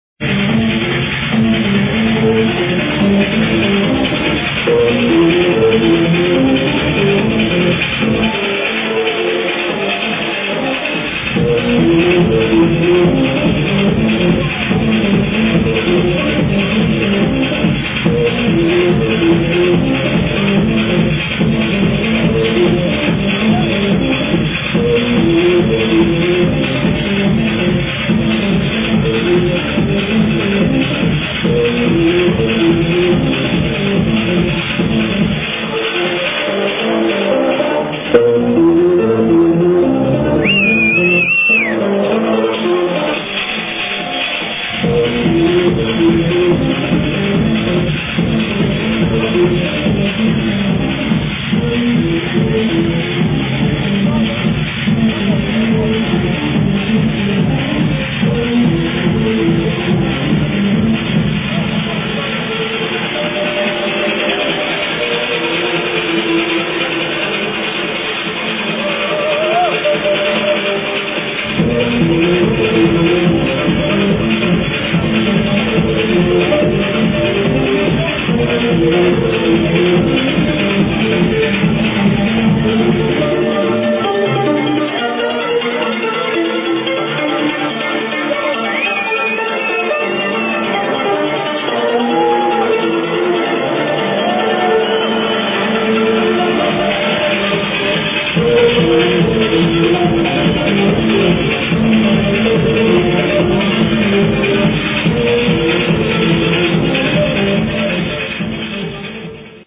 Techno ID
There's a great techno track